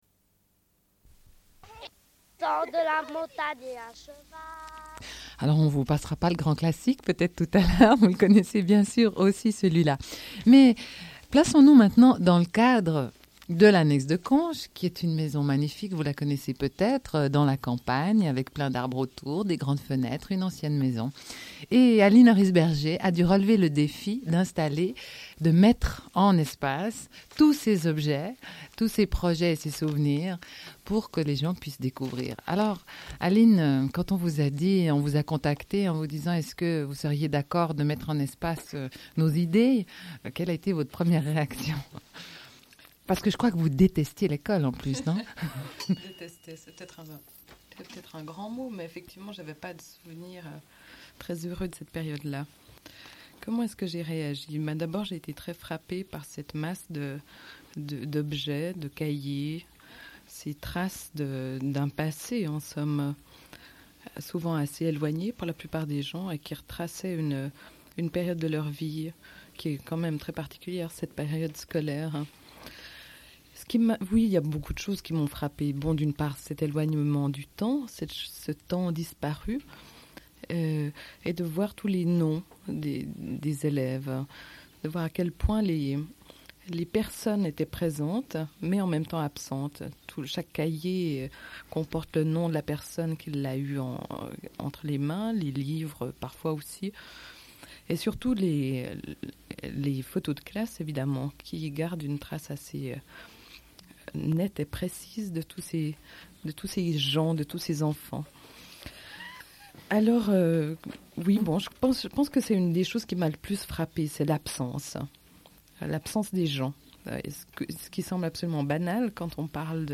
Une cassette audio, face A00:31:53